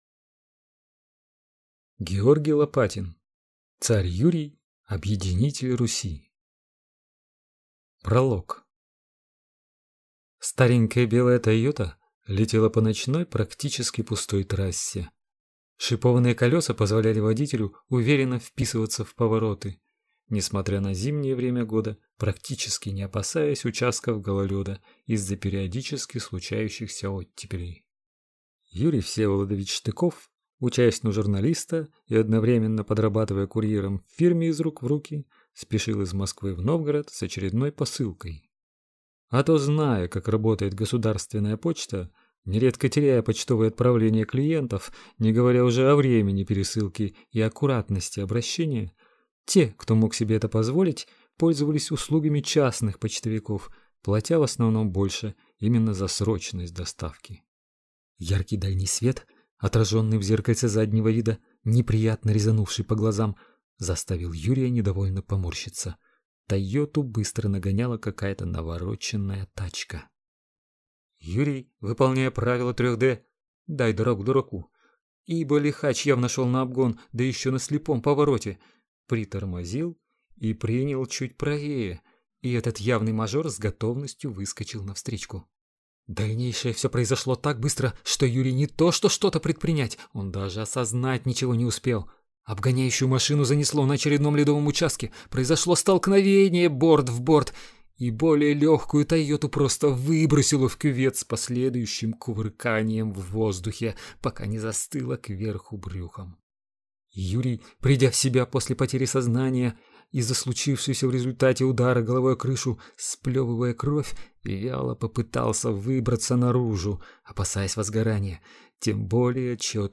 Аудиокнига Царь Юрий. Объединитель Руси | Библиотека аудиокниг